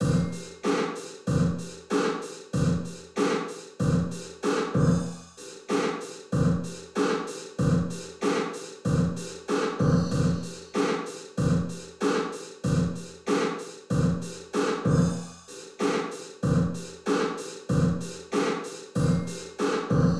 For example, here is the ‘Bathroom 1’ IR where I’ve altered the envelope of the sound using volume curves in Audacity:
With 100% wet  ‘Bathroom 1 Processed’ IR:
It gives it a more non-linear type of sound.
ir-example-drum-loop-100-wet-bathroom-2-processed.wav